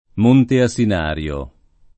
Monte Senario [m1nte Sen#rLo] o Montesenario [id.] top. (Tosc.) — un tempo, Monte Asinario [